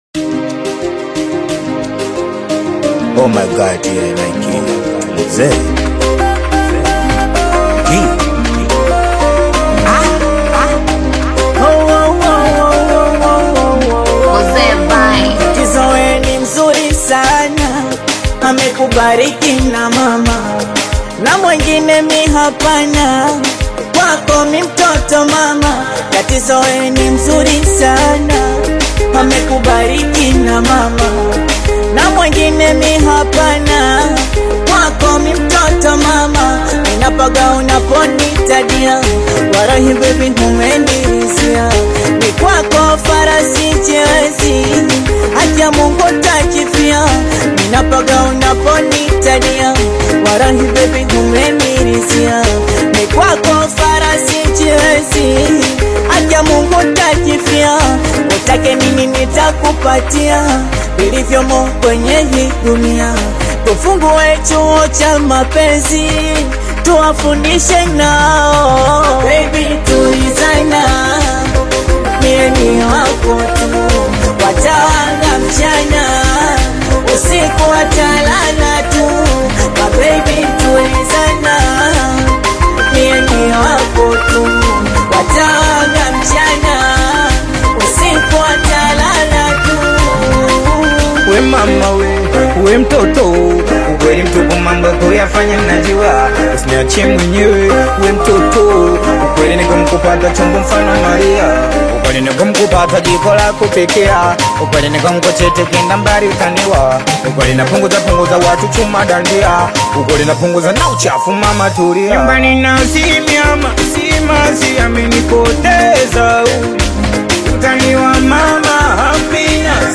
is an energetic Tanzanian Singeli/Bongo Flava collaboration